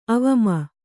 ♪ avama